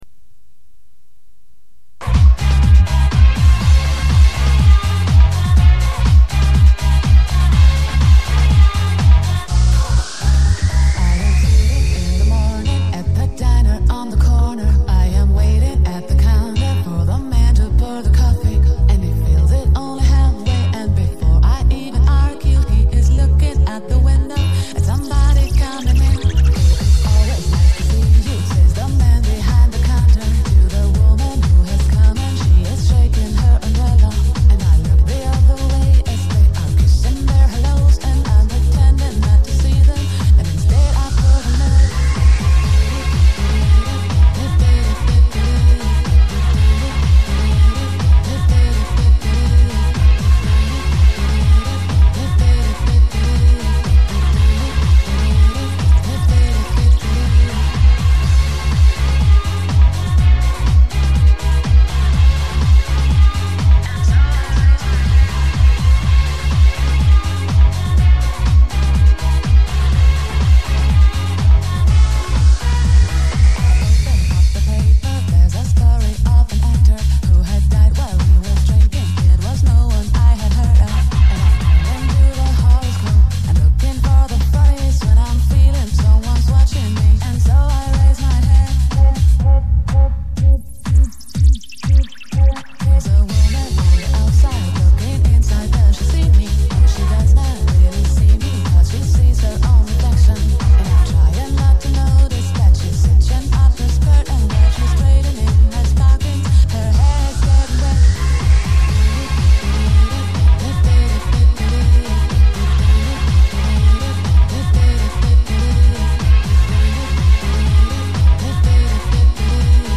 Очередные кассетные оцифровки.
ТРЕТЬЯ ЗАПИСЬ С РУССКОГО РАДИО НА ОПОЗНАНИЕ